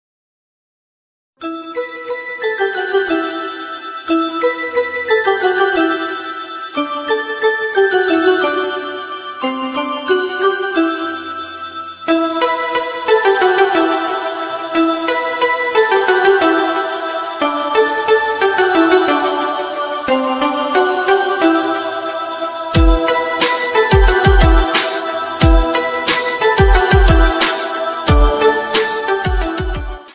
Best Instrumental Ringtones 2025